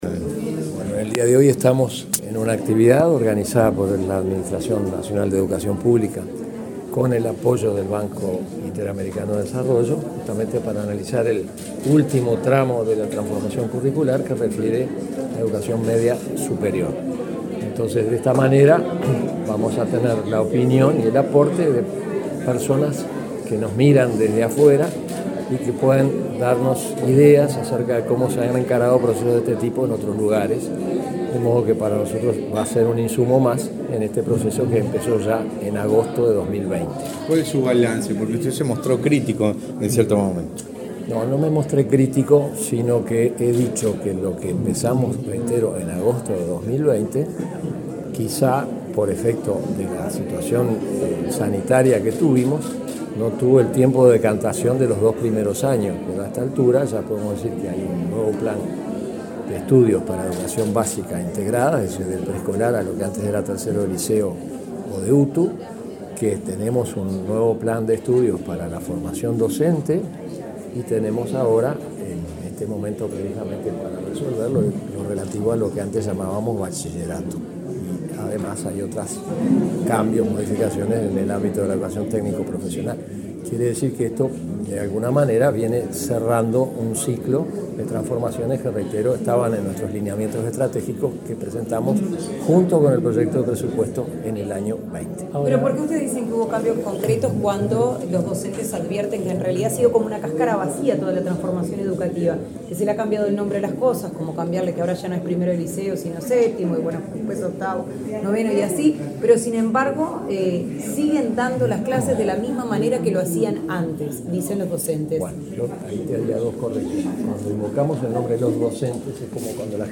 Declaraciones del presidente interino de la ANEP, Juan Gabito Zóboli
El presidente interino de la ANEP, Juan Gabito Zóboli, dialogó con la prensa, antes de participar de la apertura del seminario Nuevos Caminos para la